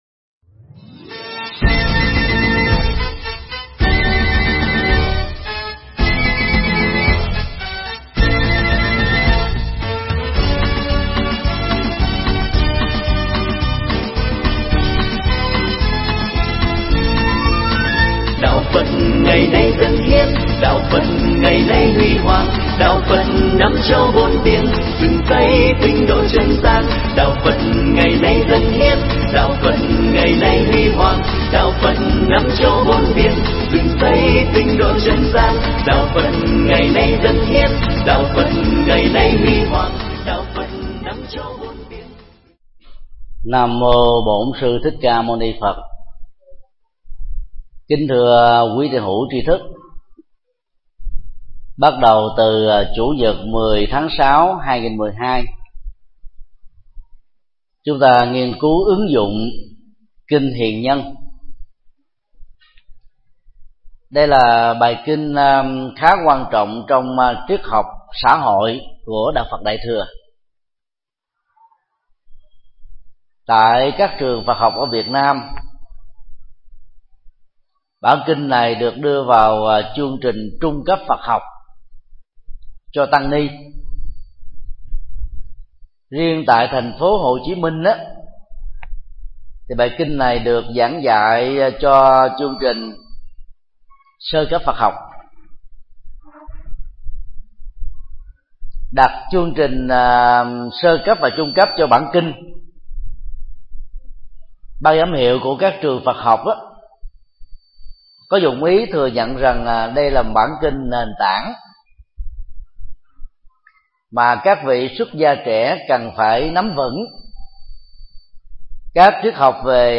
Nghe mp3 bài giảng Kinh Hiền Nhân 01: Các đức tính nên học do thầy Thích Nhật Từ giảng tại chùa Xá Lợi, ngày 10 tháng 06 năm 2012.